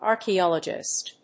/‐dʒɪst(米国英語), ˌɑ:rki:ˈɑ:lʌdʒɪst(英国英語)/
フリガナアーキーアラジィスト